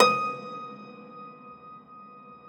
53o-pno15-D3.wav